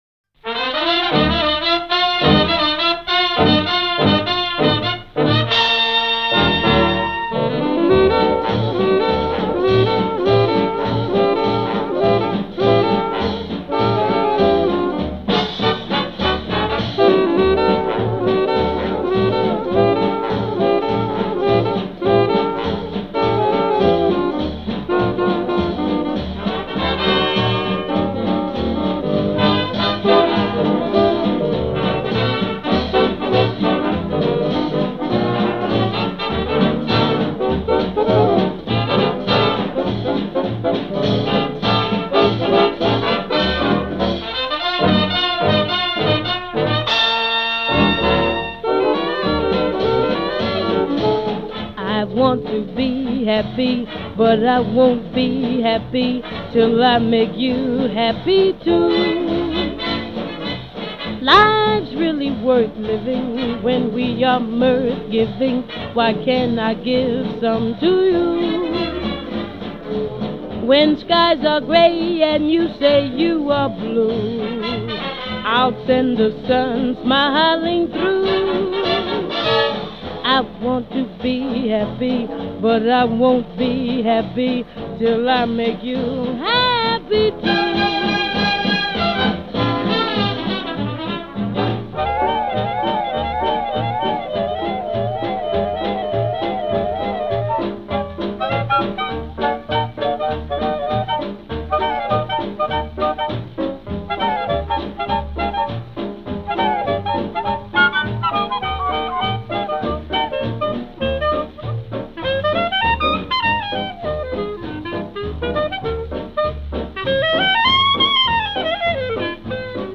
jazzy rendition
Genre: Musical